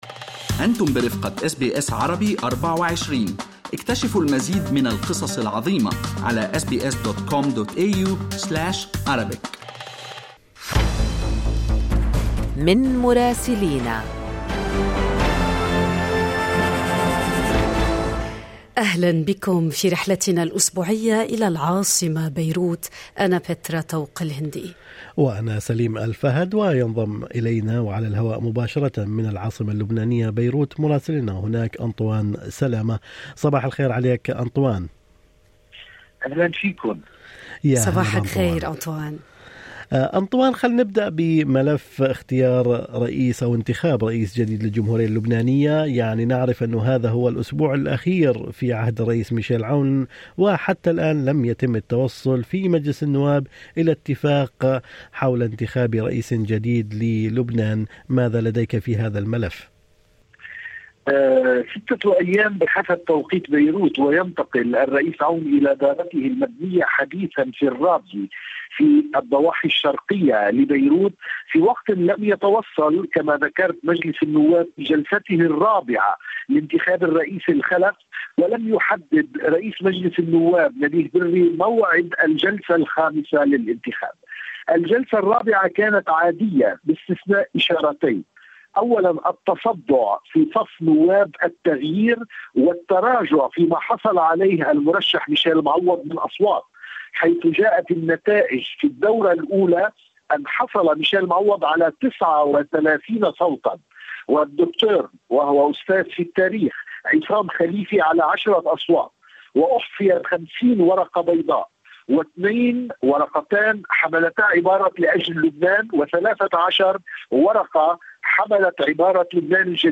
يمكنكم الاستماع إلى تقرير مراسلنا في لبنان بالضغط على التسجيل الصوتي أعلاه.